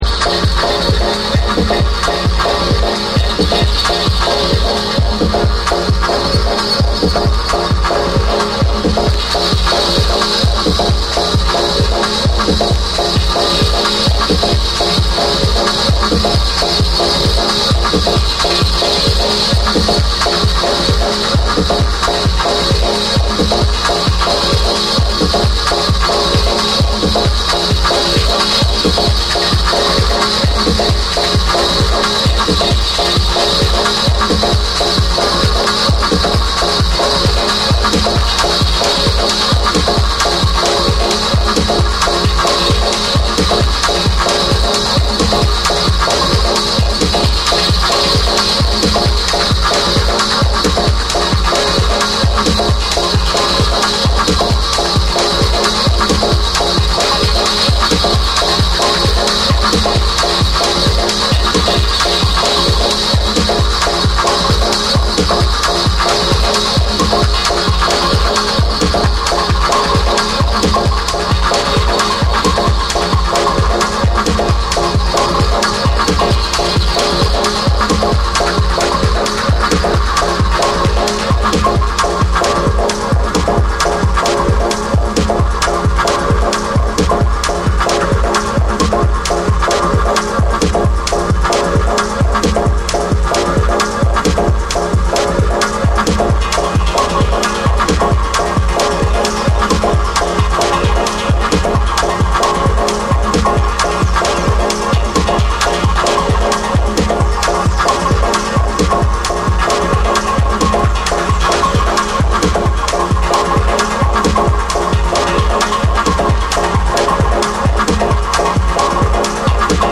TECHNO & HOUSE